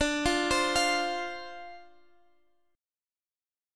F5/D chord